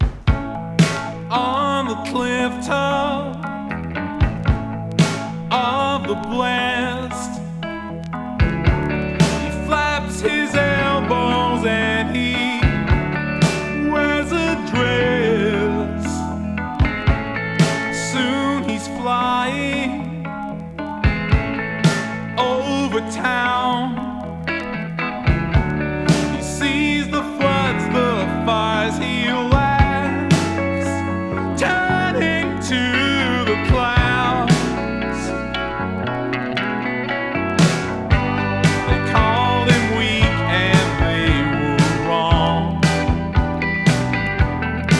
粒揃いに良い曲良いメロディが並び、コーラスワークも嬉しい良盤。
Rock, Pop, Indie　France　12inchレコード　33rpm　Stereo